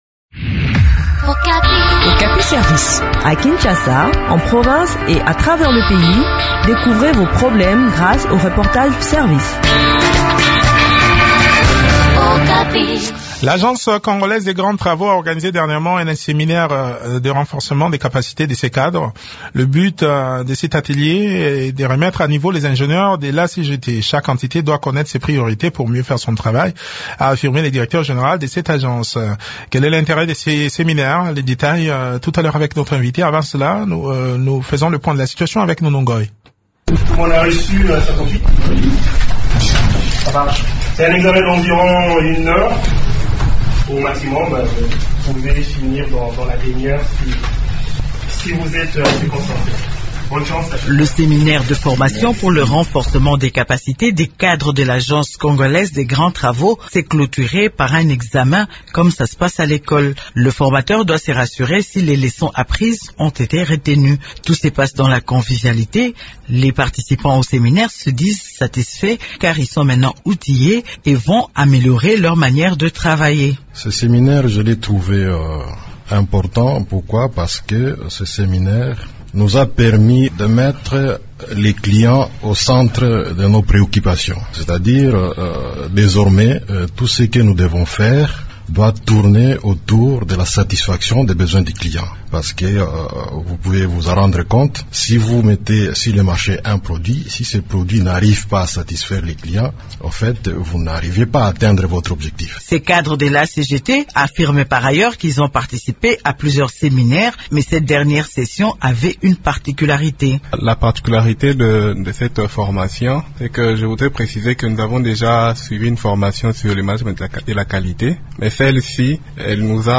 Le point sur le déroulement de cette session de formation dans cet entretien